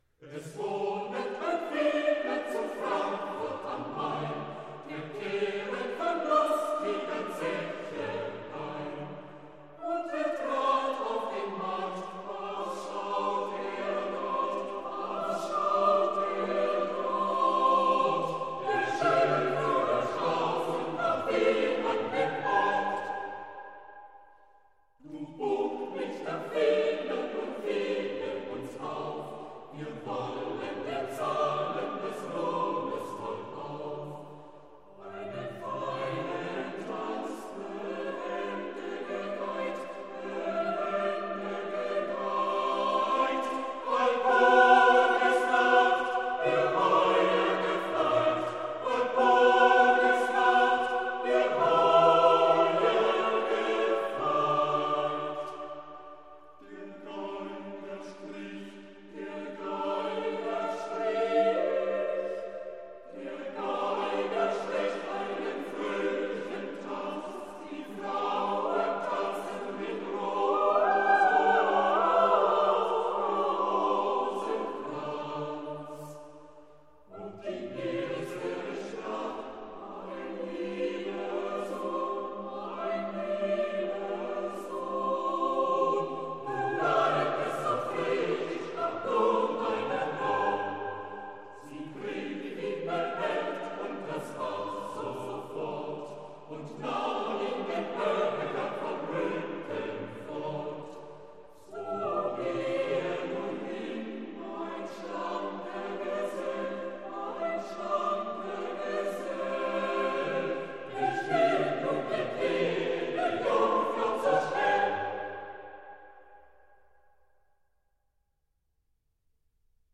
Brahms, Johannes - 6 Songs and Romances, Op.93a Free Sheet music for Choir (SATB)
Style: Classical